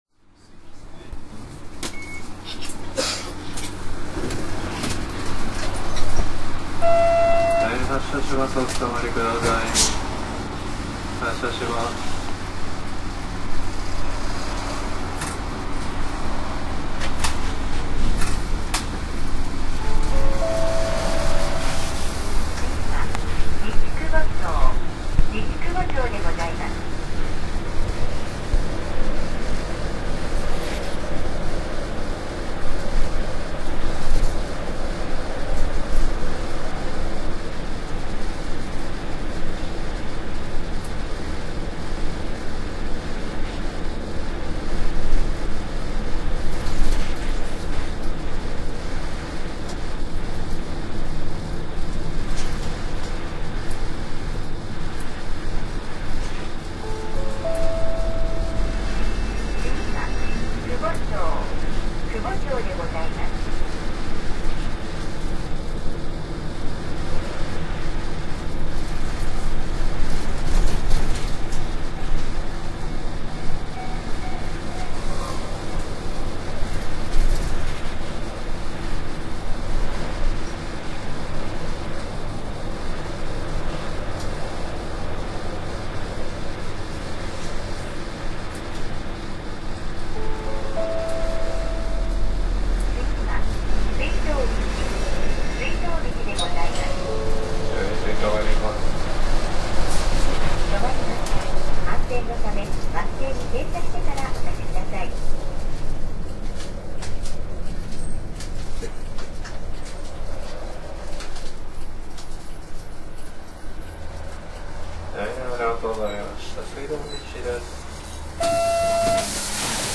燃料に軽油ではなくＣＮＧ（圧縮天然ガス）を採用した、非常に静かなエンジン音が特徴な車輛です。